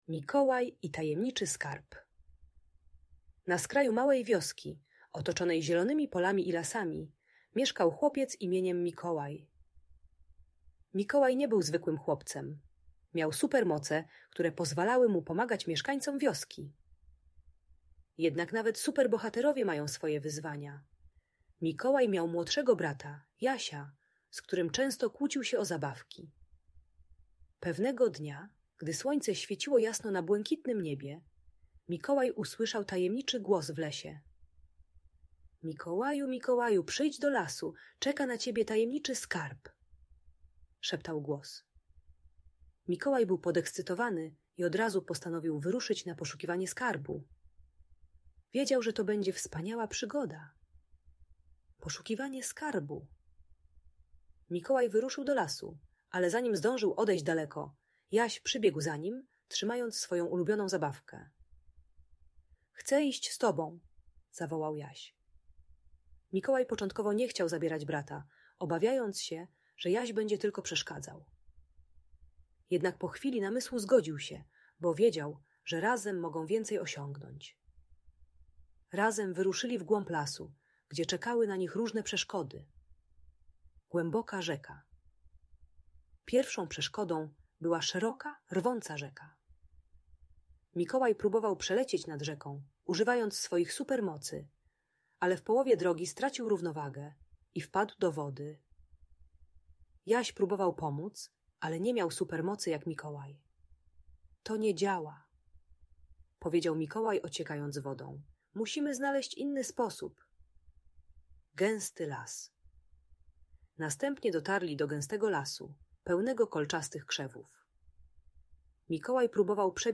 Przygoda Mikołaja i Jasia: Opowieść o Skarbie - Audiobajka